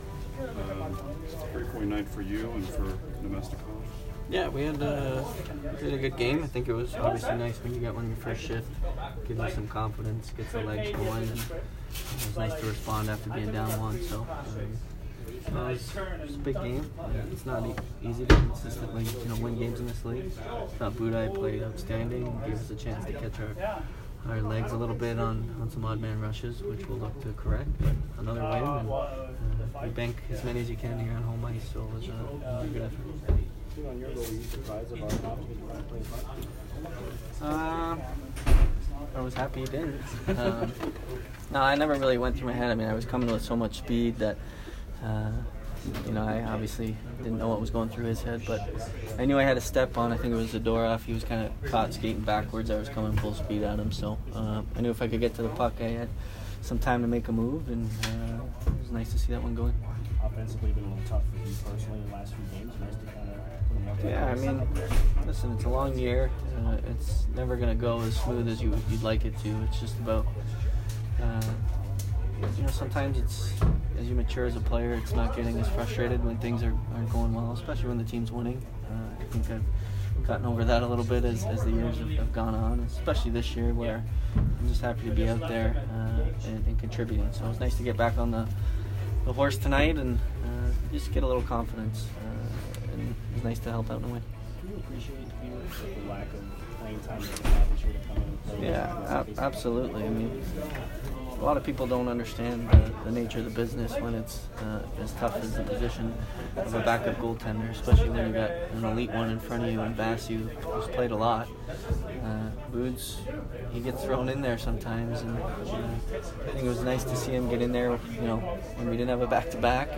Steven Stamkos Post-Game 12/7